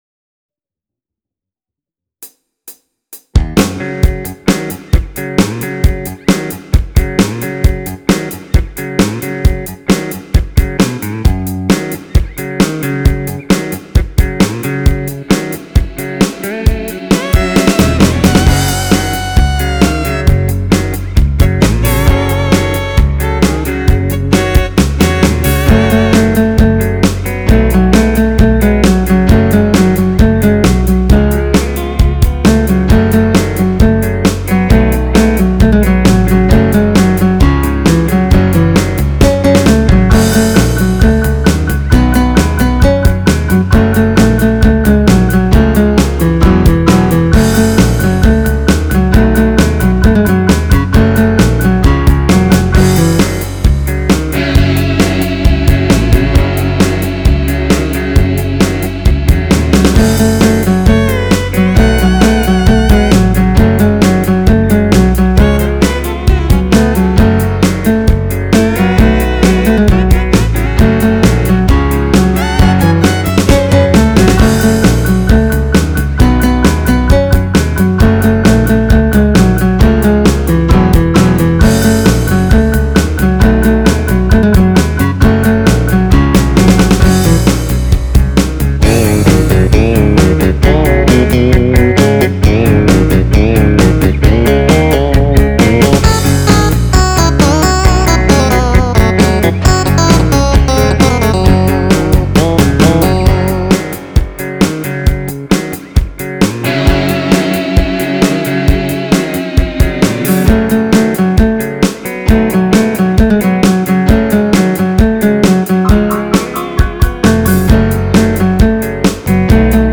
[Country List]